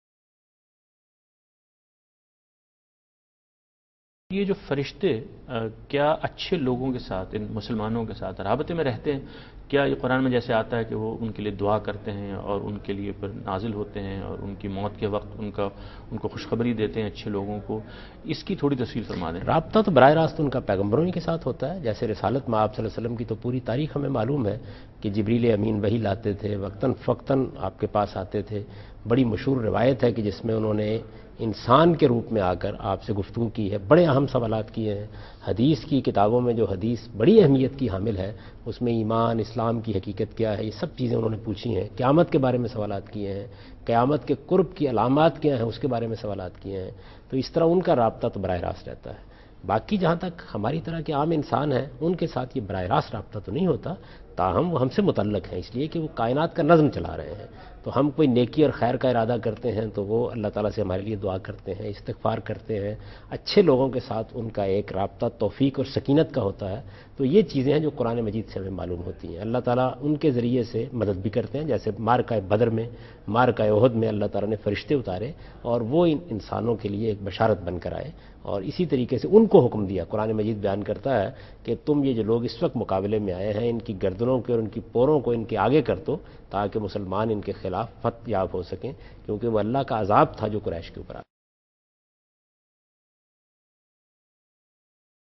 Category: TV Programs / Dunya News / Deen-o-Daanish / Questions_Answers /
Javed Ahmad Ghamdi answers a question about "Angels' Interaction with the Muslims" in program Deen o Daanish on Dunya News.